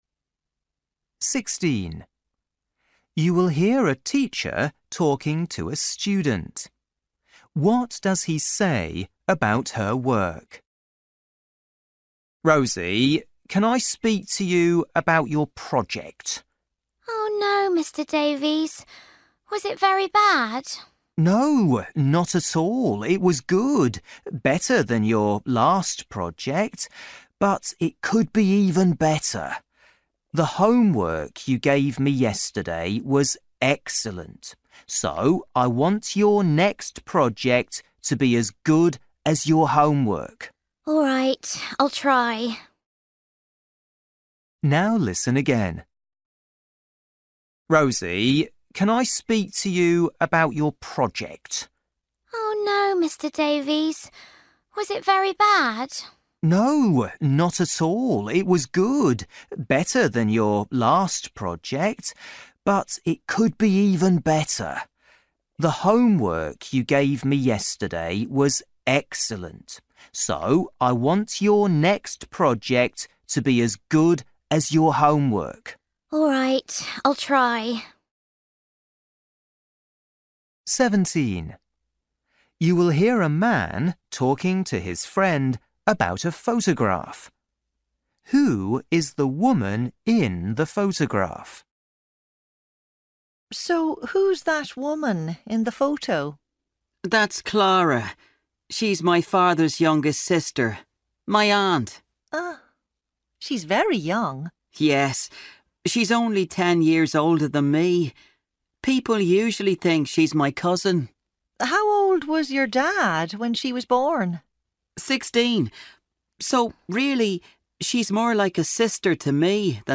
Listening: everyday short conversations
16   You will hear a teacher talking to a student. What does he say about her work?
17   You will hear a man talking about to his friend about a photograph. Who is the woman in the photograph?
20   You will hear two friends talking about their day. What did they do first?